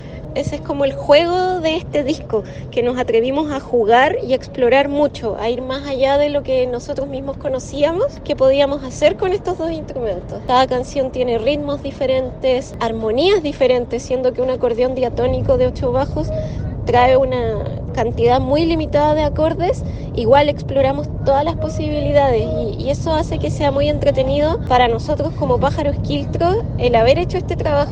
El violín y el acordeón diatónico vuelven a fundirse
melodías que se acercan a la música para el teatro y circo